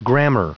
Prononciation du mot grammar en anglais (fichier audio)